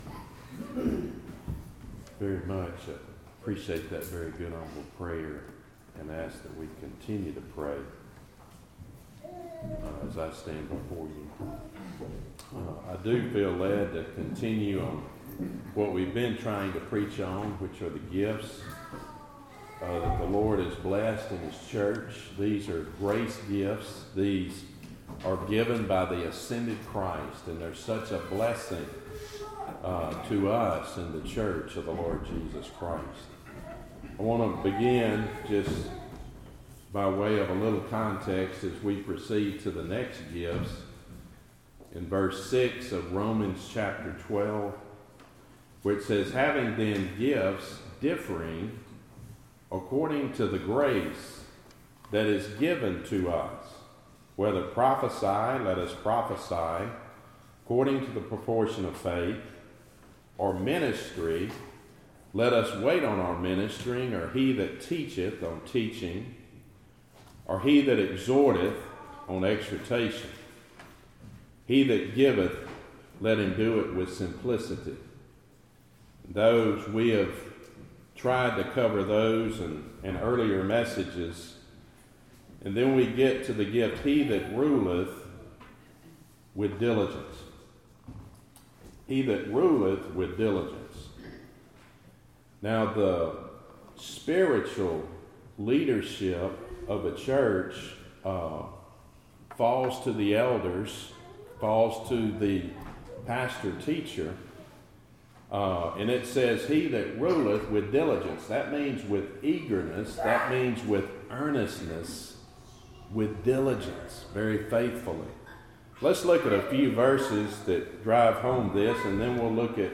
Series: Gifts in the Church Topic: Sermons